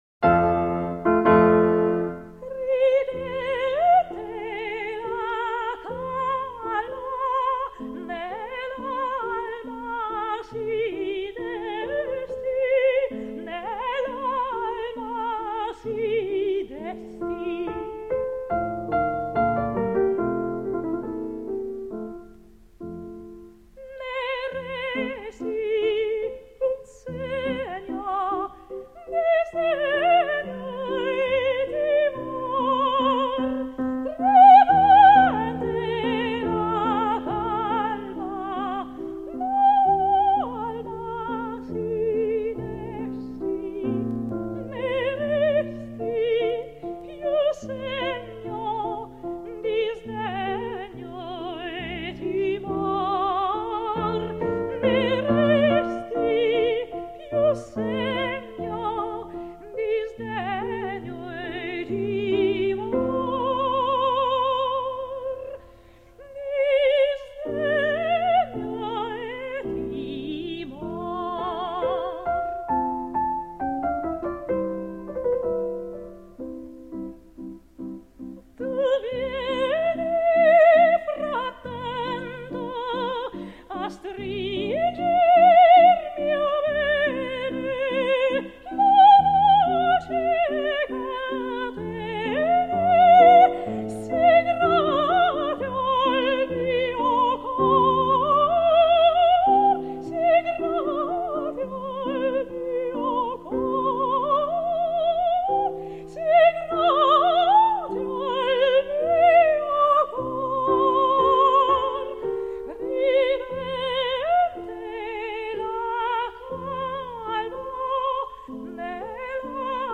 女 高 音
钢    琴
她早年专攻花腔女高音，后以演唱抒情女高音为主，尤其擅长演唱艺术歌曲。